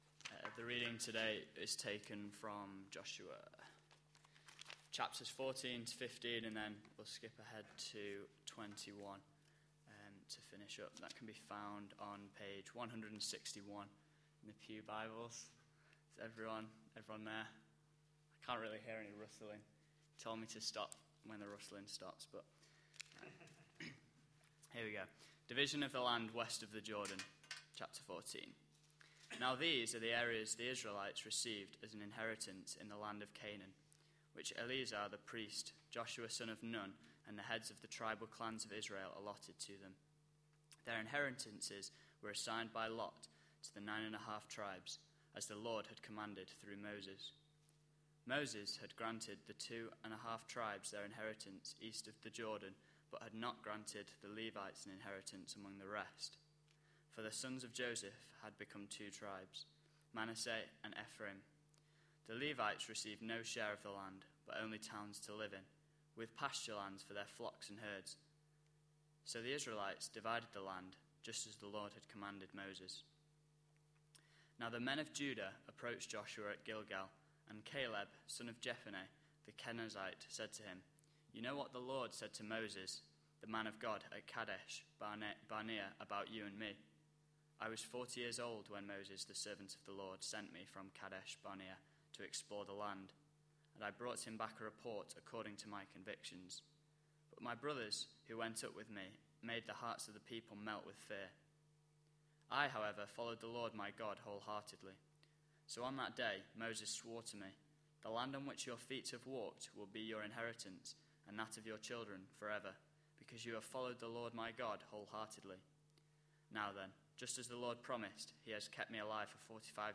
A sermon preached on 1st July, 2012, as part of our Entering God's Rest series.